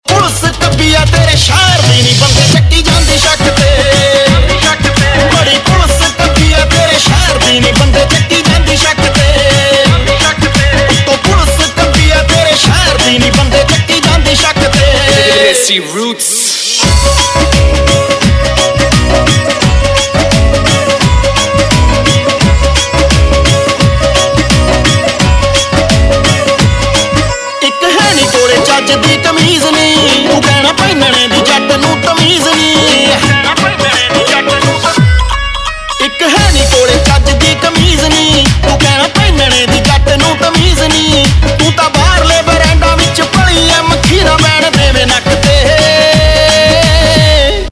Punjabi Mp3 Tone